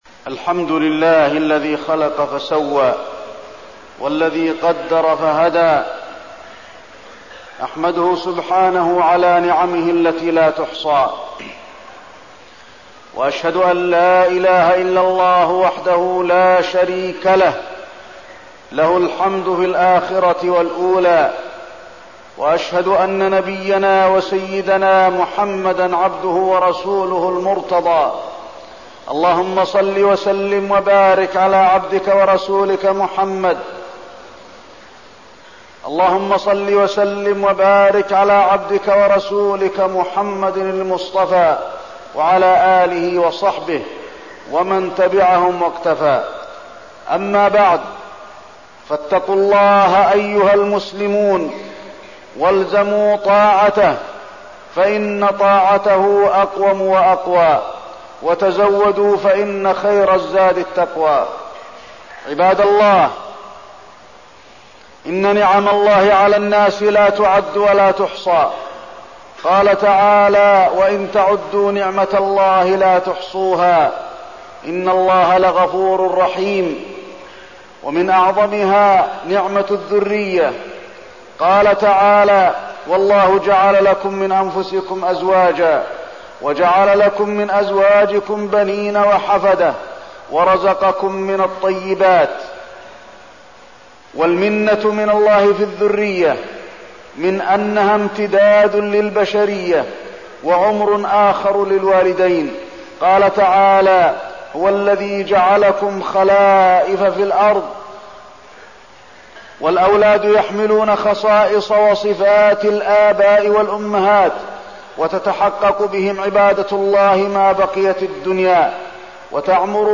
تاريخ النشر ٢٩ ربيع الثاني ١٤١٤ هـ المكان: المسجد النبوي الشيخ: فضيلة الشيخ د. علي بن عبدالرحمن الحذيفي فضيلة الشيخ د. علي بن عبدالرحمن الحذيفي تربية الأولاد